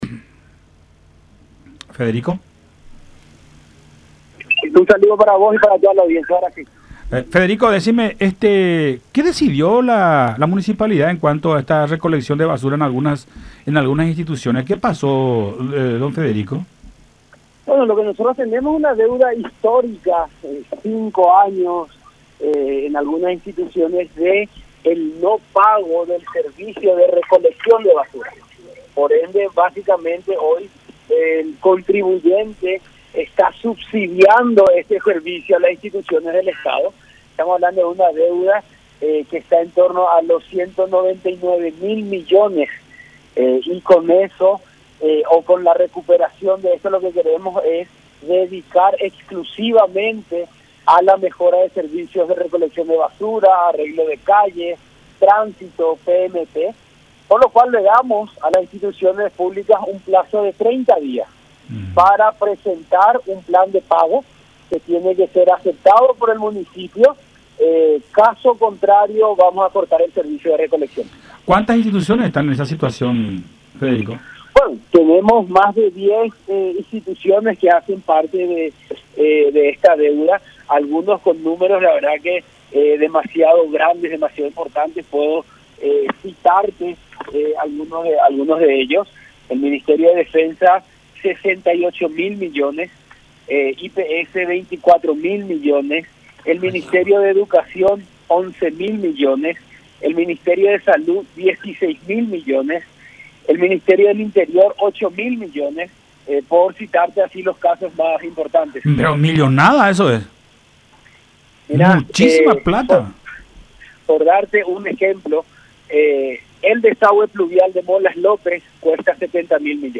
En el caso de que no se proceda al pago de estas deudas, nos veremos obligados a tomar la decisión drástica de no recoger más los residuos”, dijo Mora en diálogo con Todas Las Voces a través de La Unión.